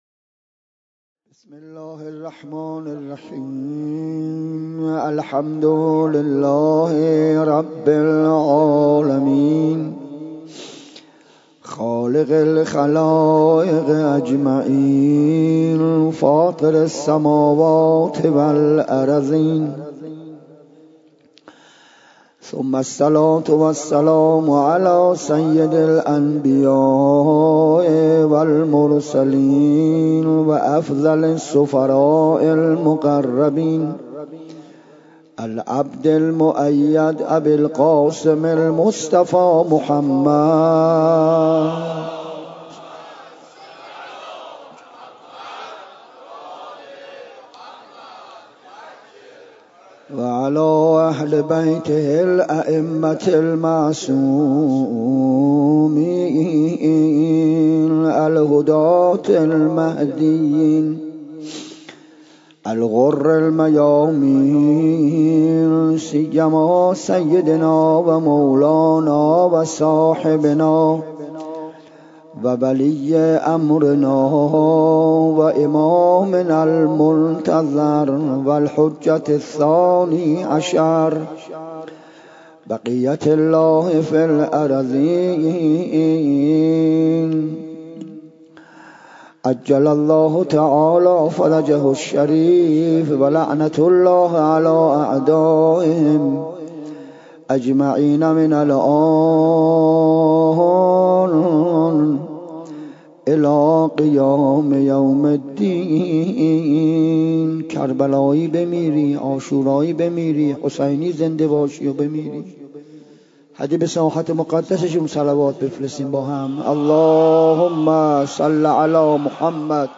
سخنرانی شب هفتم محرم 1402